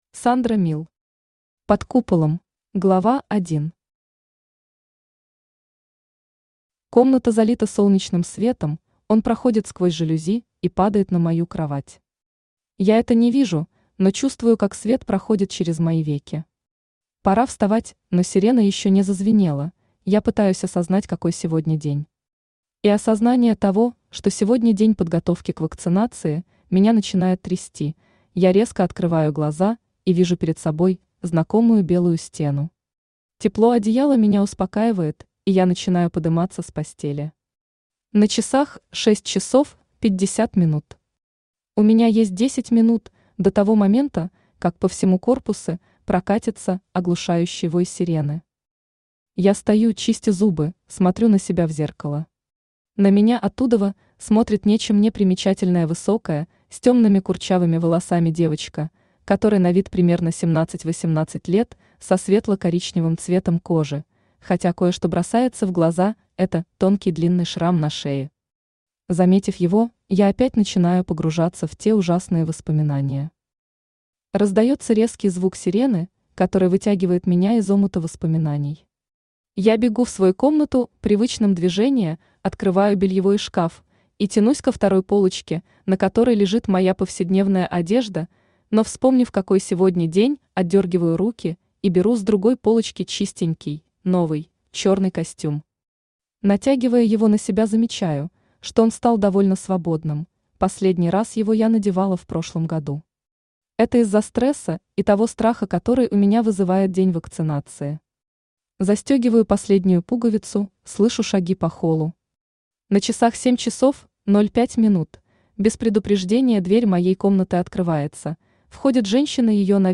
Aудиокнига Под куполом Автор Сандра Мил Читает аудиокнигу Авточтец ЛитРес.